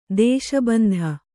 ♪ dēśa bandha